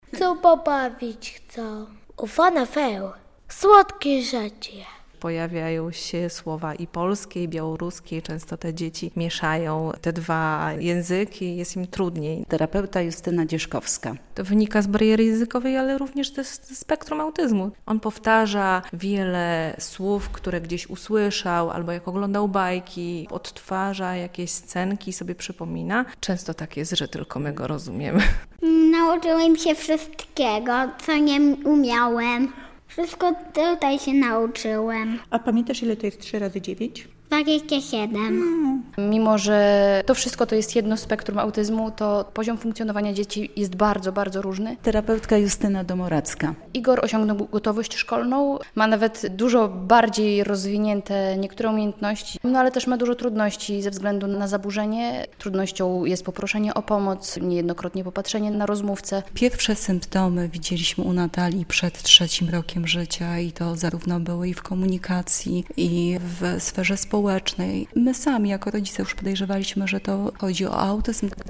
O podopiecznych białostockiego oddziału Krajowego Towarzystwa Autyzmu - relacja